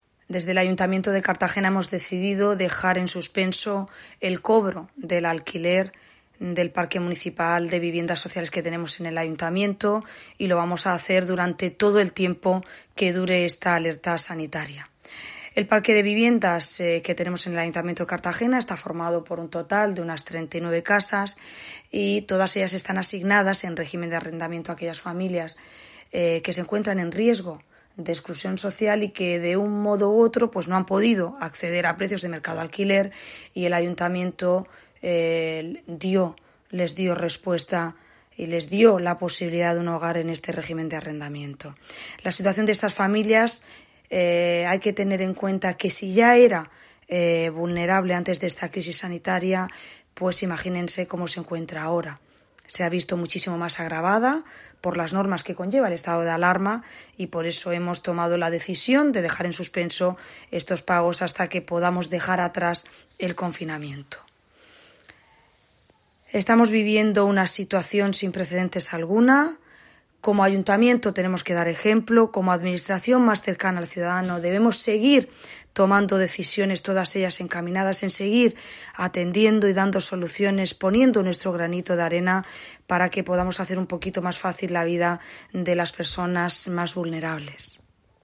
Comparecencia de la alcaldesa por internet para explicar nuevas medidas en torno al coronavirus.